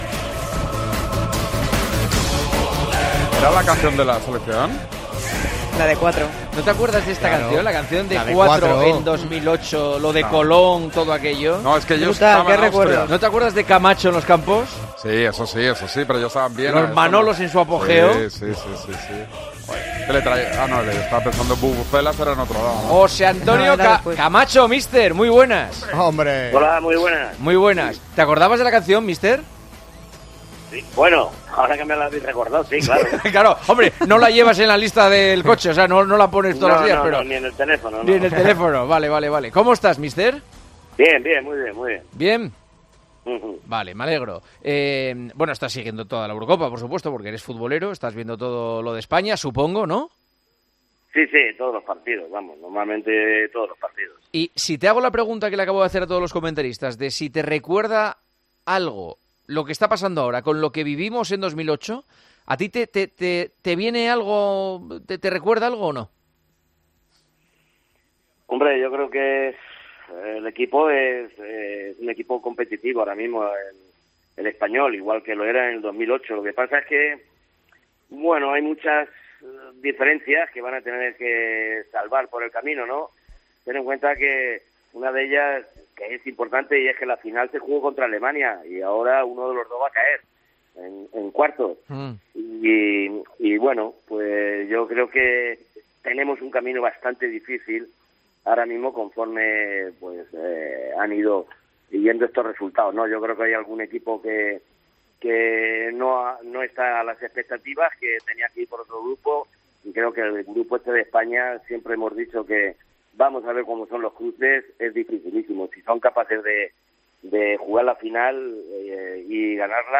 Juanma Castaño charló con el ex seleccionador y ex comentarista de las retransmisiones de España en Mediaset: "La España de ahora es igual de competitiva que la de 2008".
Entrevista a José Antonio Camacho, ex seleccionador de España y ex comentarista en Mediaset